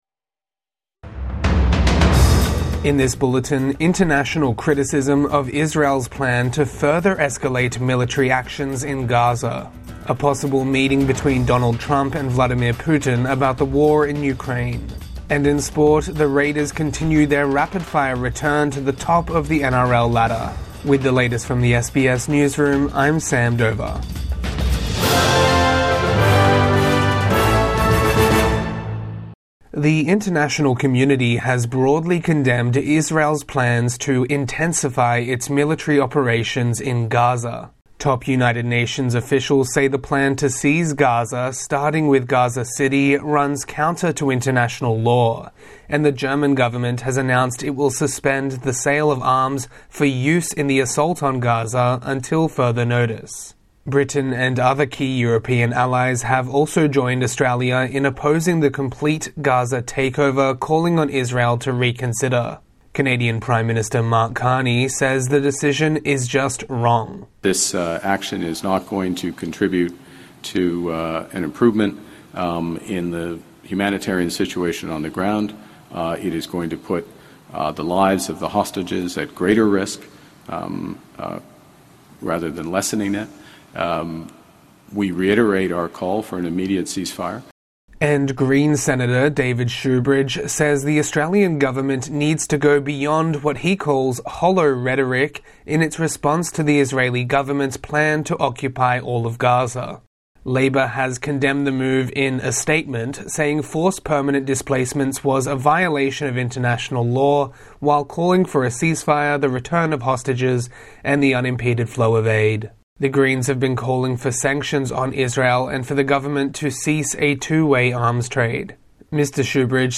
International community condemns Israel's Gaza takeover plan | Morning News Bulletin 9 August 2025